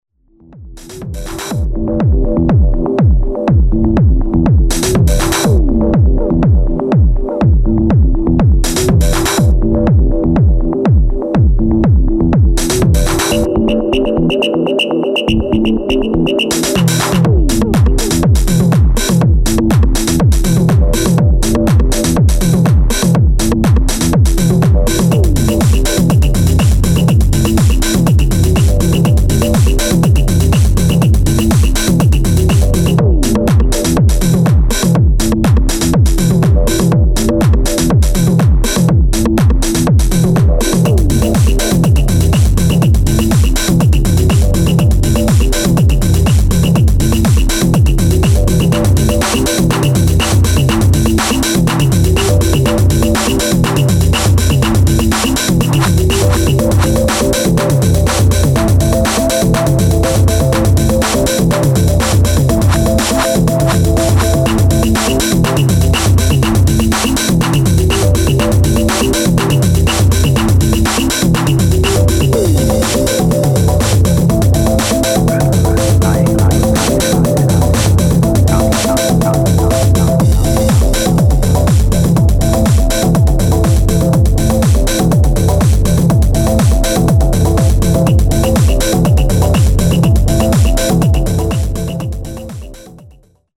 DEEP HOUSE | LO-FI | JUNGLE
ディープ・フリーク・ハウスからレイヴィーなローファイ・ジャングルを収録した